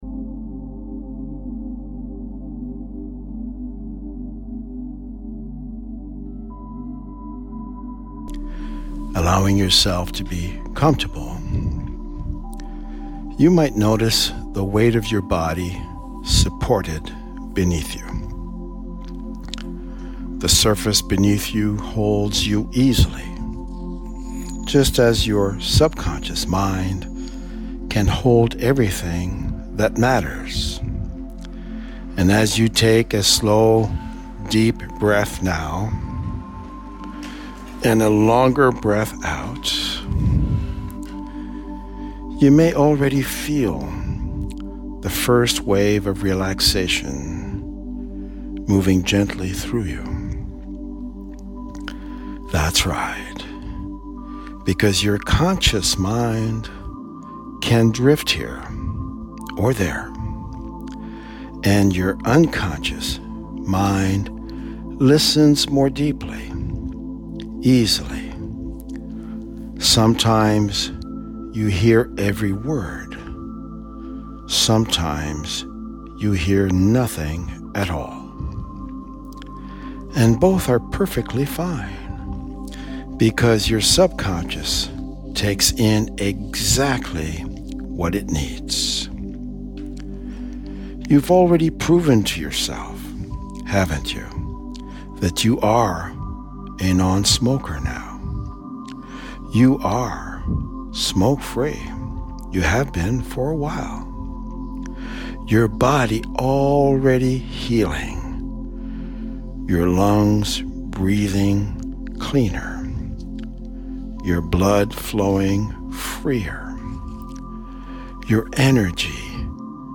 The following is a 19-minute hypnosis recording.
cravings buster theta music.mp3